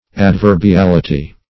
\Ad*ver`bi*al"i*ty\